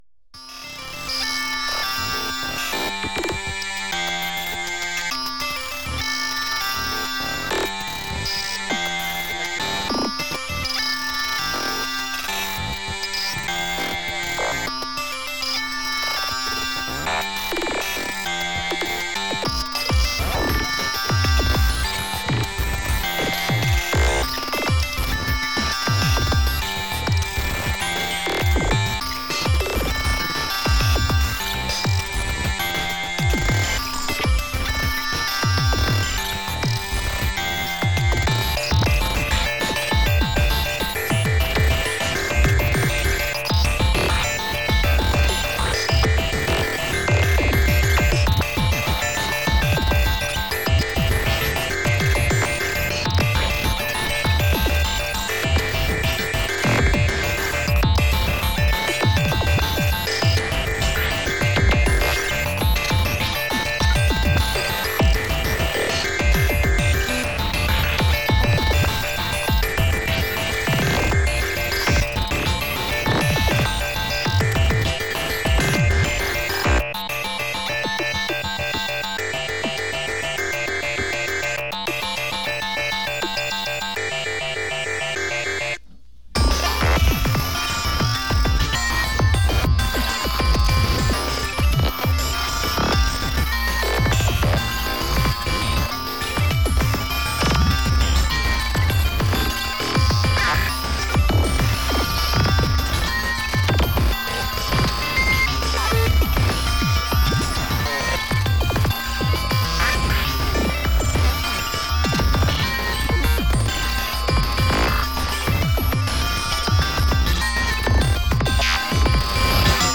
Avant-Garde Experimental Rock